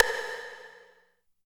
90 STICK  -R.wav